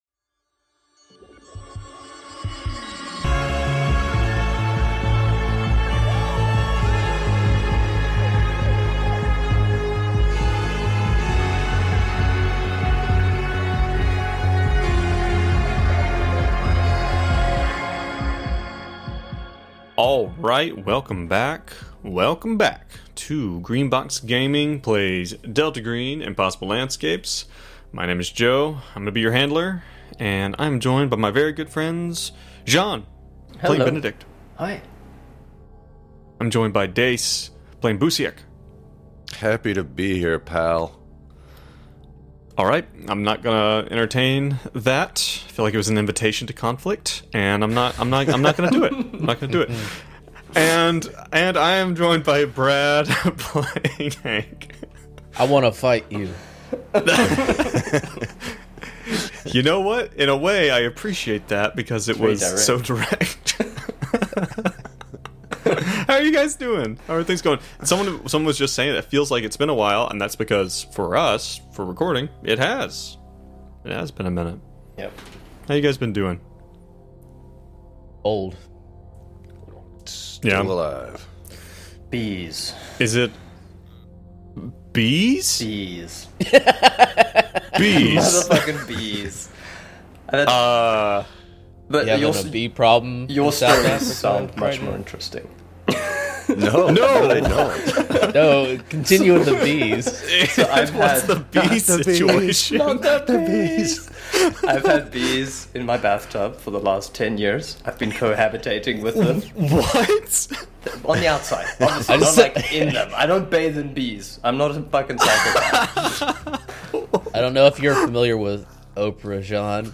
Four friends launch into a game of horror, mystery, and conspiracy where regular people fight against the unknown at the expense of their relationships, sanity, and lives.
We play our recorded session live on Twitch every other Saturday!